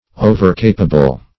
Search Result for " overcapable" : The Collaborative International Dictionary of English v.0.48: Overcapable \O`ver*ca"pa*ble\, a. Too capable.